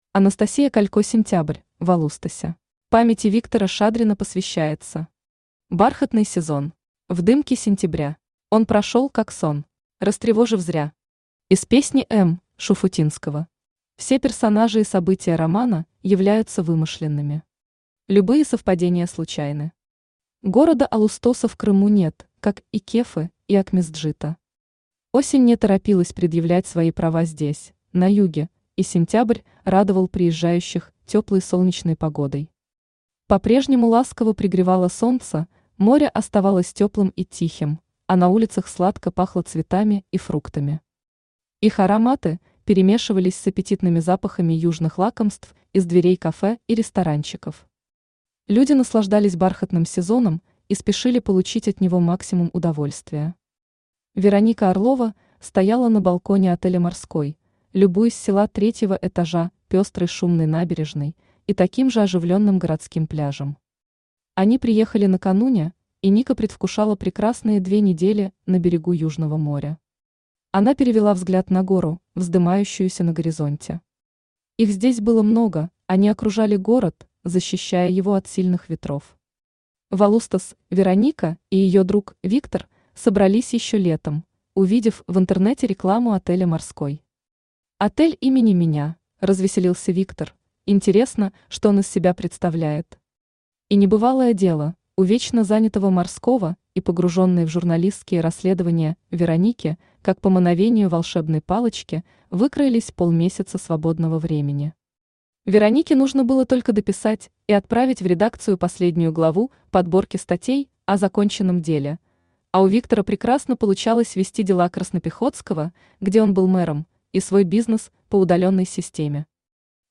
Аудиокнига Сентябрь в Алустосе | Библиотека аудиокниг
Aудиокнига Сентябрь в Алустосе Автор Анастасия Александровна Калько Читает аудиокнигу Авточтец ЛитРес.